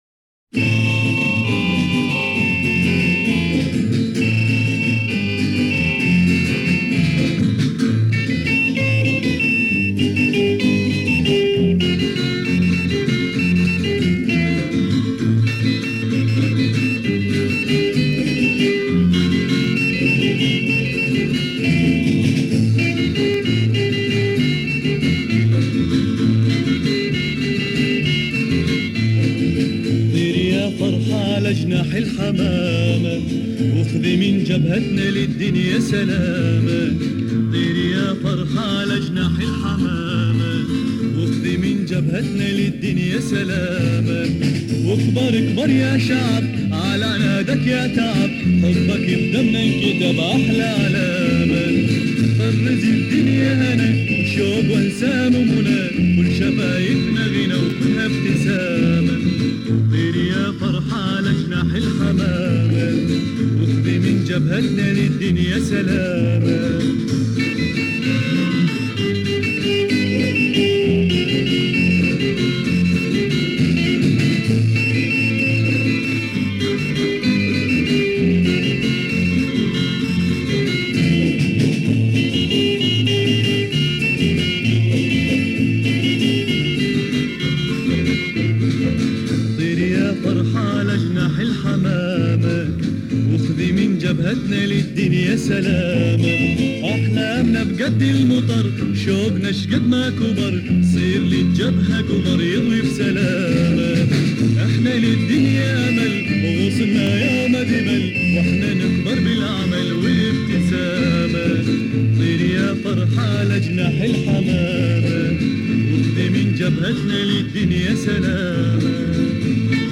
That is what we call political songs.